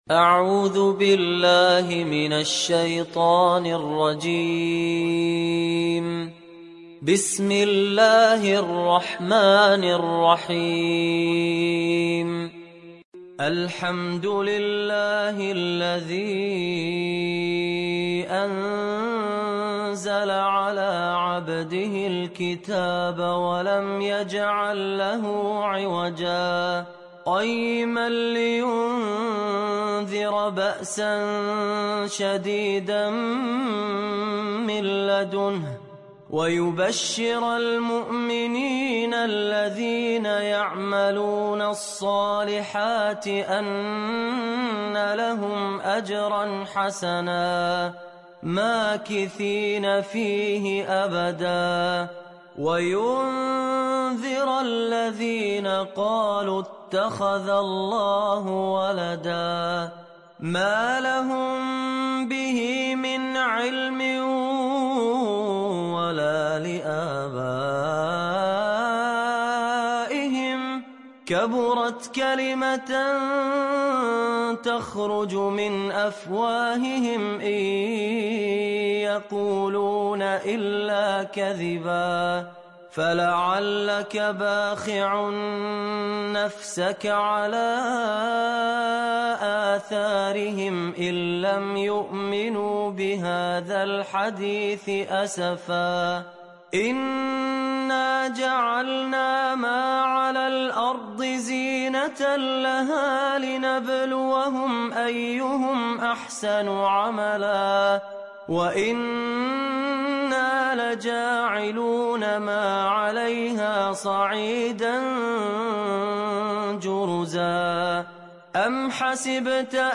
Sourate Al Kahf Télécharger mp3 Fahad Alkandari Riwayat Hafs an Assim, Téléchargez le Coran et écoutez les liens directs complets mp3